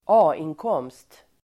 Ladda ner uttalet
A-inkomst substantiv, income taxable at source Uttal: [²'a:ink'åm:st] Böjningar: A-inkomsten, A-inkomster Definition: inkomst av tjänst el. eget företag (income from employment or business undertaking)